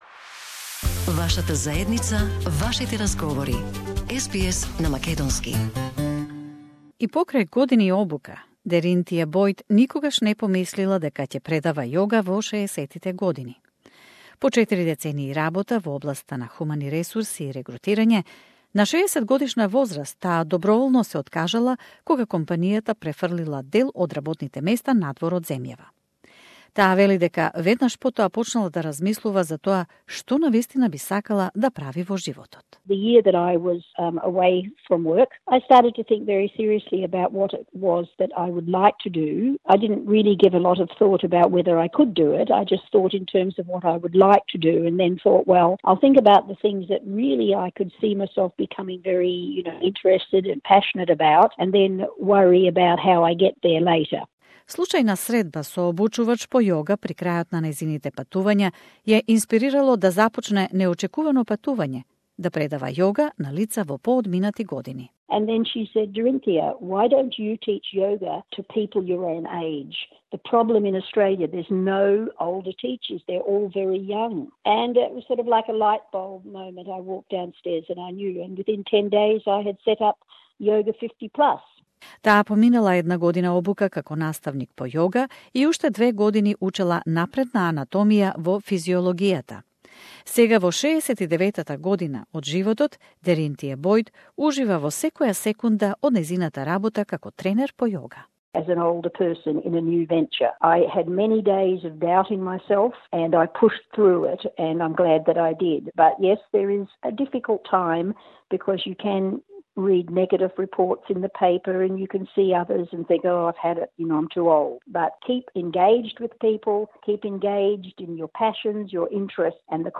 Whether youre looking for work, want to start your own business or fulfil a long-term passion - starting a new career later in life can be challenging. We meet a woman who became a yoga teacher in her 60s, career coaches and an older worker recruiter for tips and trends to help you make a successful career transition